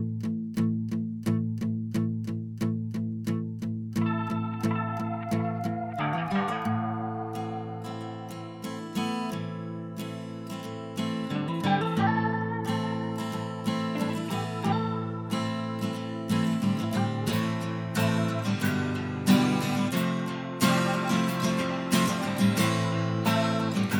no Backing Vocals Comedy/Novelty 4:08 Buy £1.50